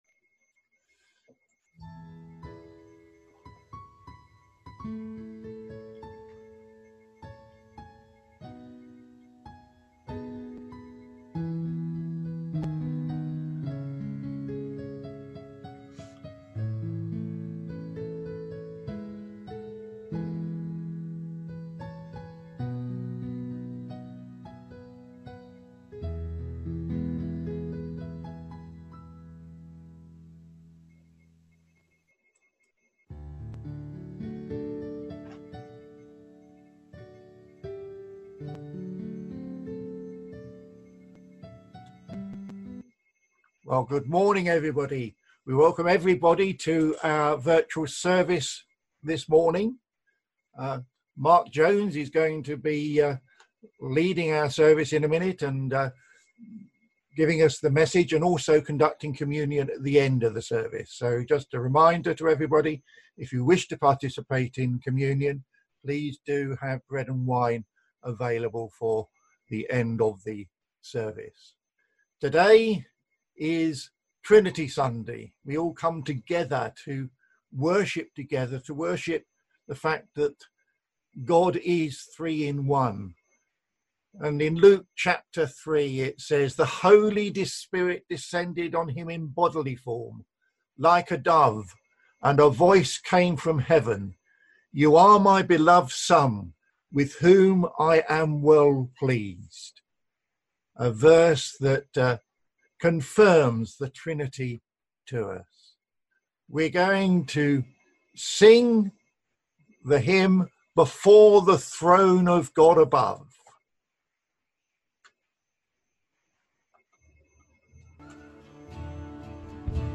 Virtual Church service Sunday 7th June 2020
Virtual-Service-Kingsthorpe-Baptist-and-Methodist-Churches-7-June-2020-Communion.mp3